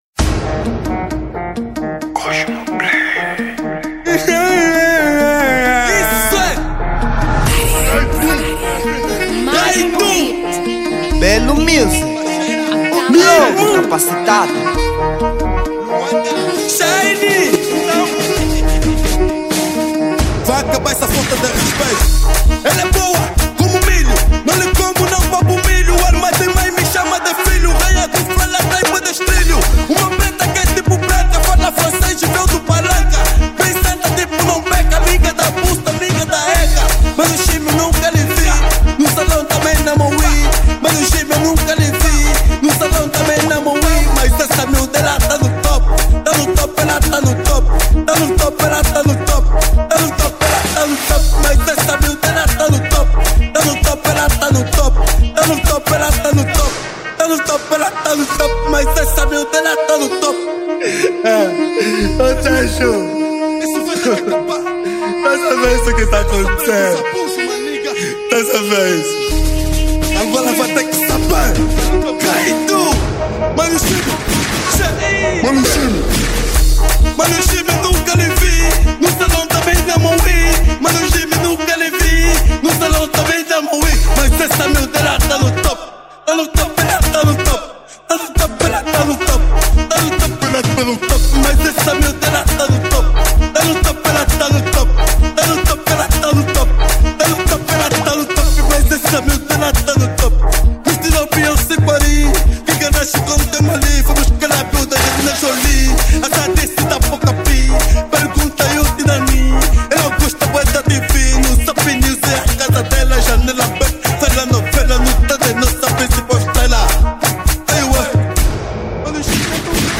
Género: House